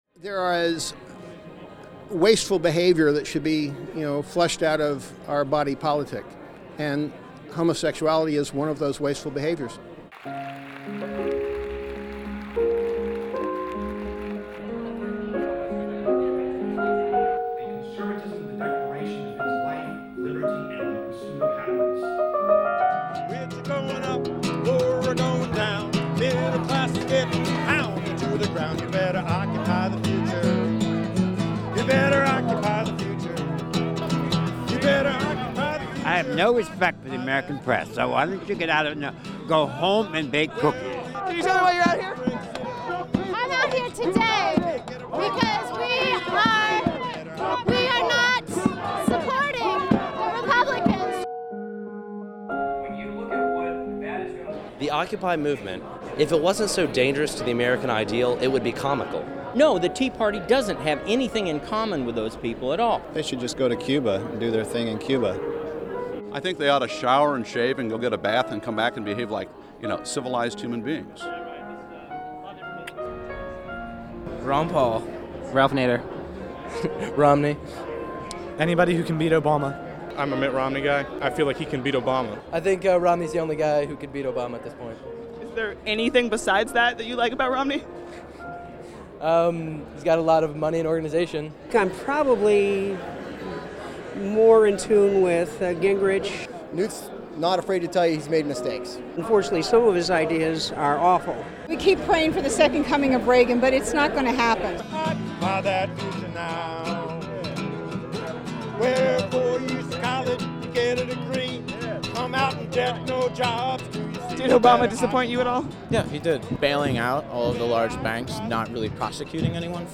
CPAC is the premier annual gathering of the conservative movement, but this year not all the action was inside the convention center.